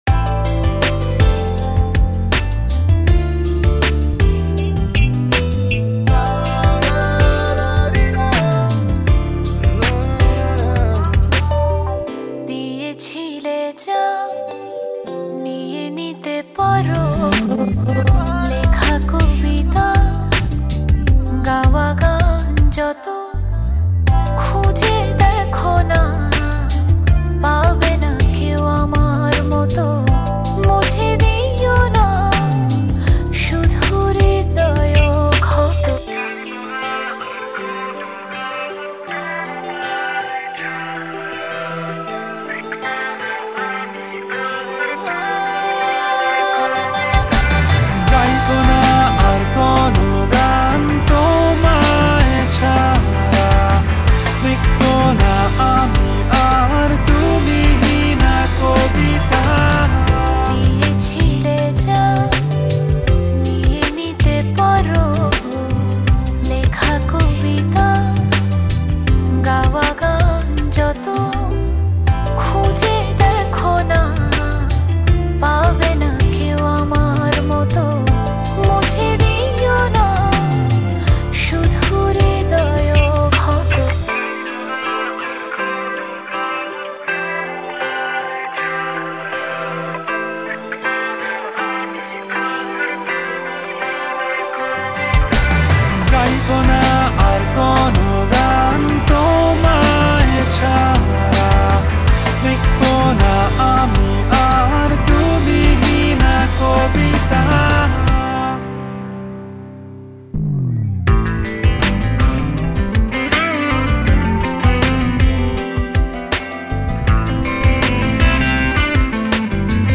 I am really liking the saxophone play in the songs.
28 Comments | Bangla Music | Tagged: , , , , | Permalink